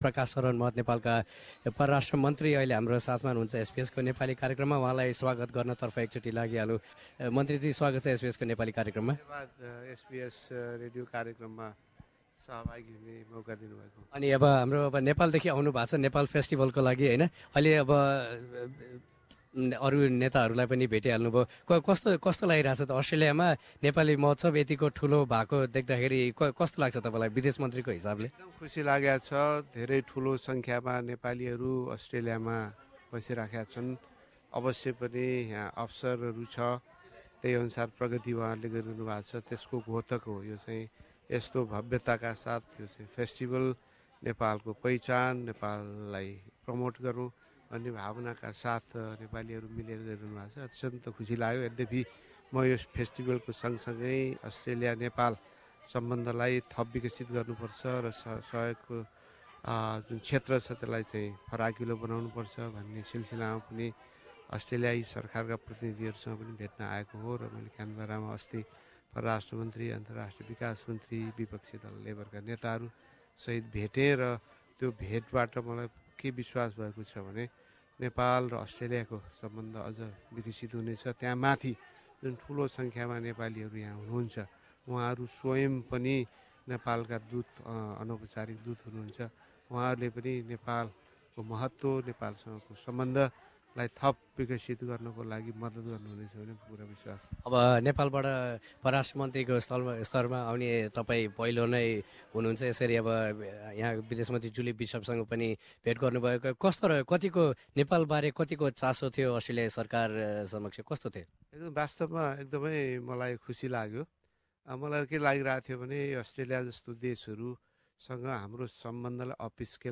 He spoke to SBS Nepali.